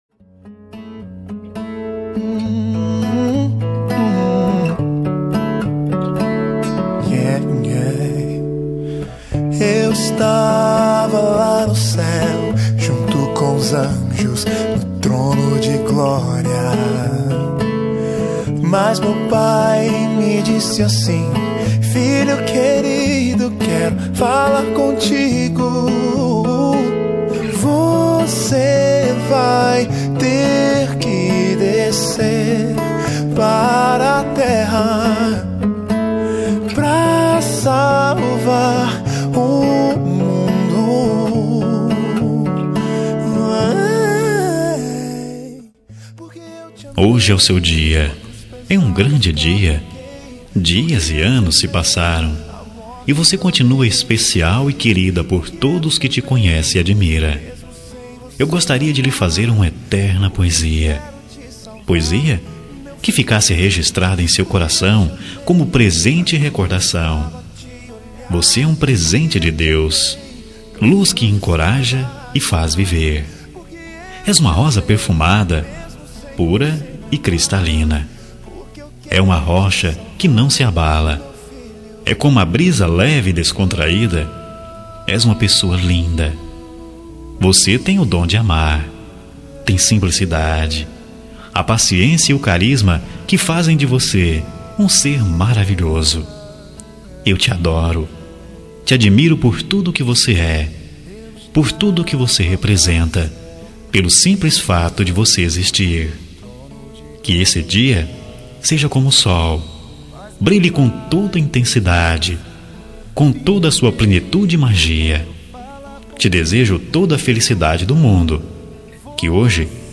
Aniversário de Irmã – Voz Masculina – Cód: 6209